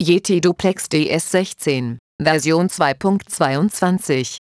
Ich habe mal die Einschaltansage angepasst :)